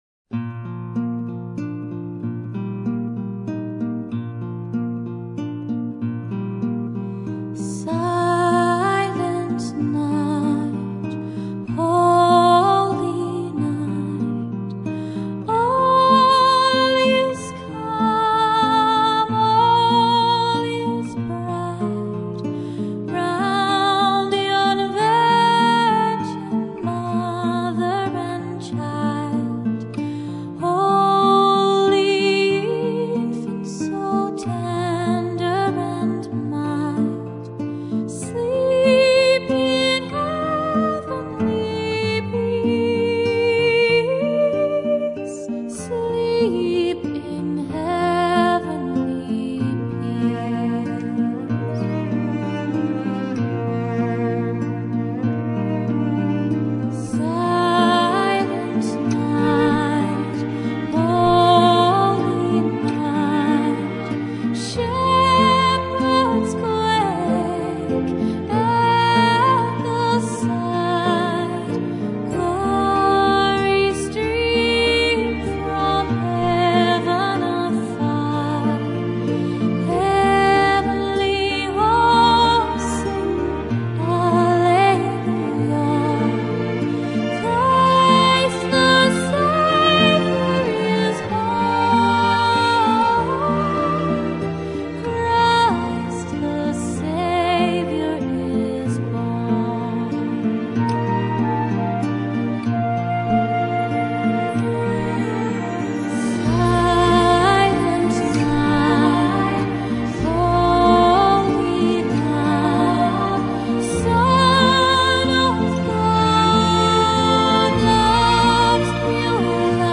Classicas